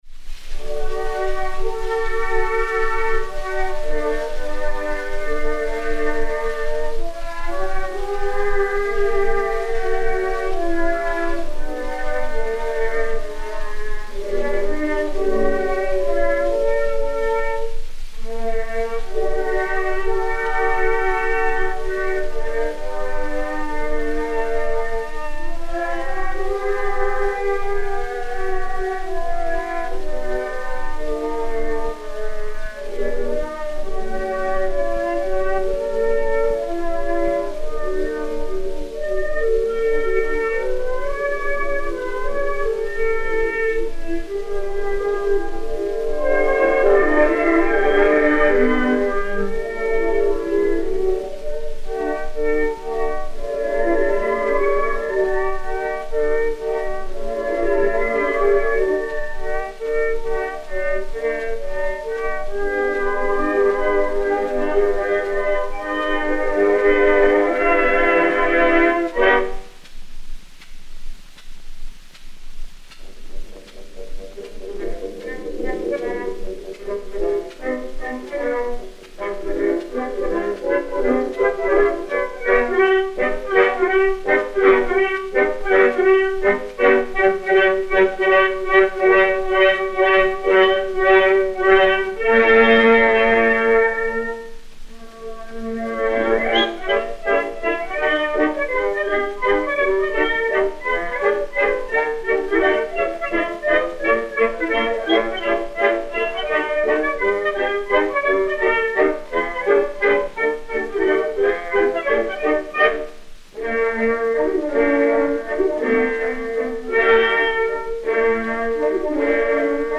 Orchestre dir.
Pathé saphir 90 tours n° 7147-4, réédité sur 80 tours n° 6460, enr. à Paris vers 1912